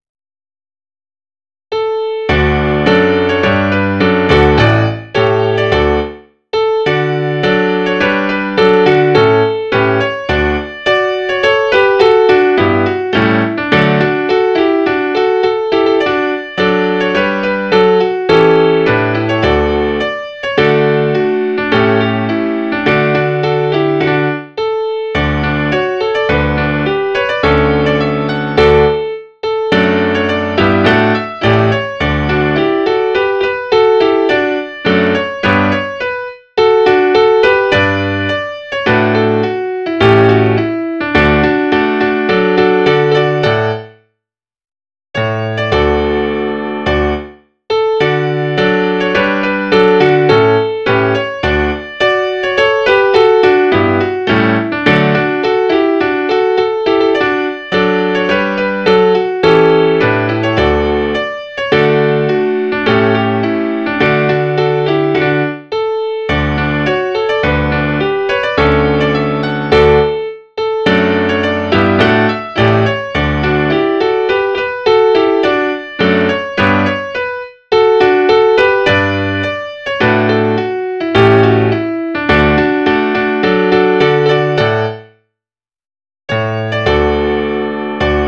The melody is an old air The Fox Sleeps .
adores.mid.ogg